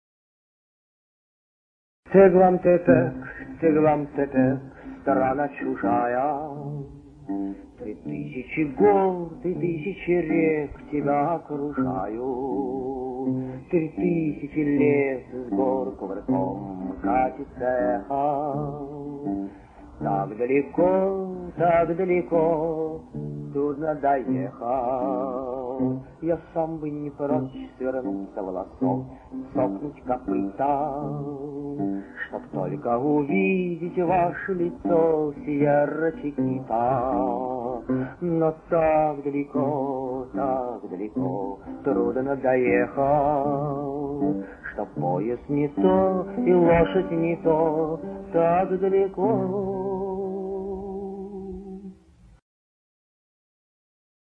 На мелодию той самой Мексиканской песни, по которой названо стихотворение С.Кирсанова. Вот Окуджава поёт, нямс, нечто для него старинное: